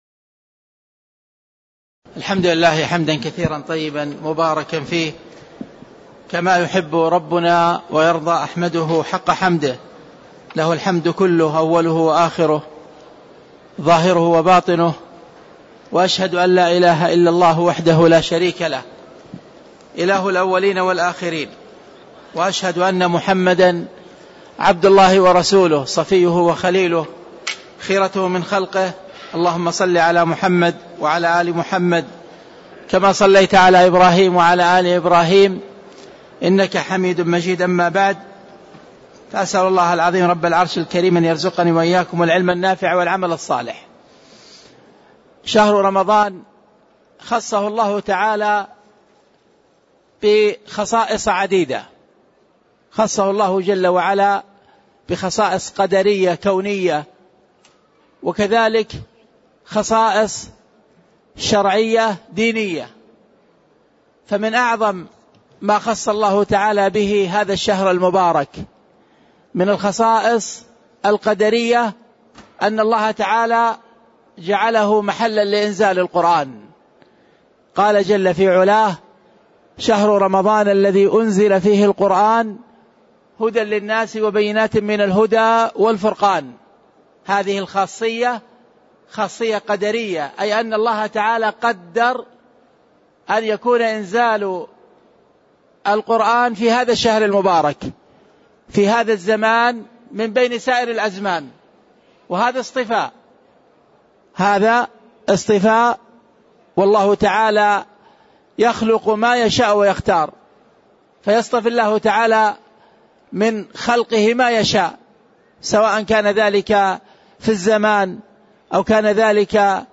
تاريخ النشر ٢٠ شعبان ١٤٣٦ هـ المكان: المسجد النبوي الشيخ